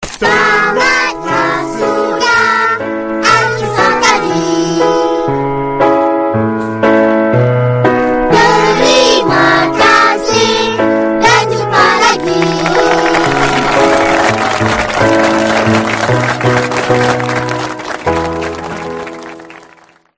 tamatlah sudah Meme Sound Effect
Category: Movie Soundboard